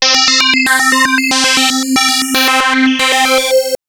002_Synth-FMCycesPad-c3.wav